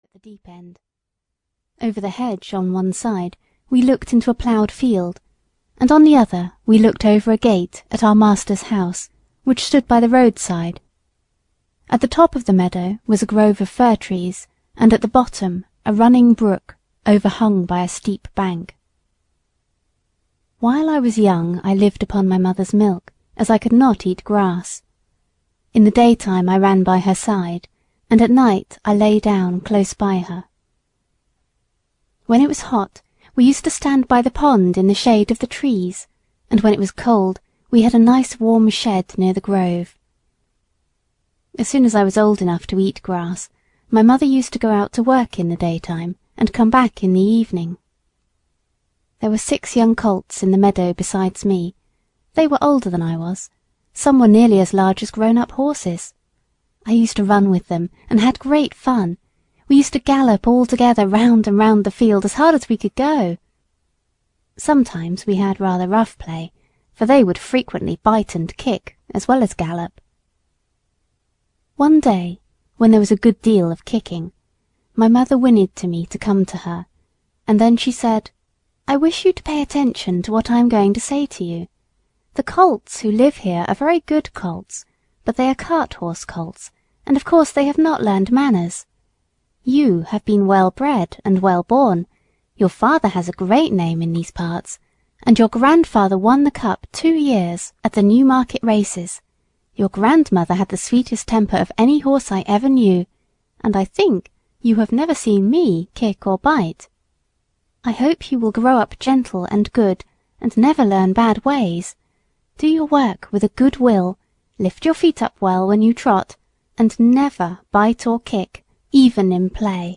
Black Beauty (EN) audiokniha
Ukázka z knihy